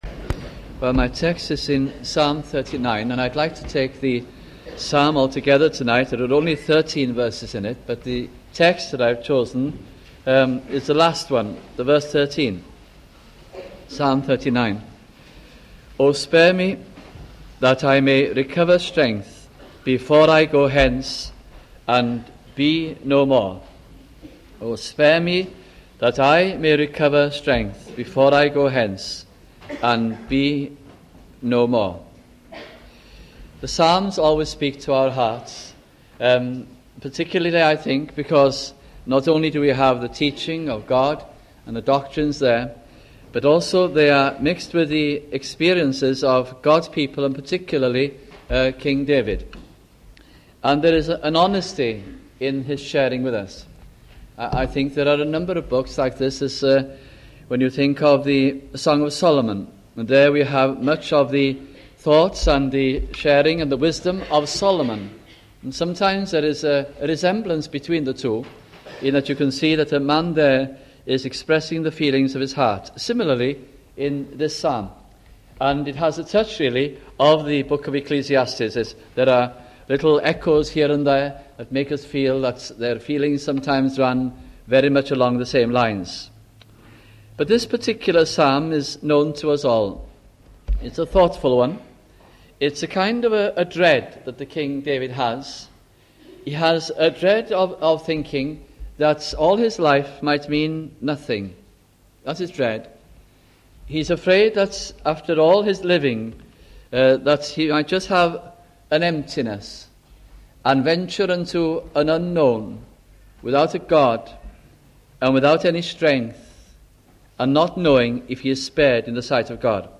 » Psalms Gospel Sermons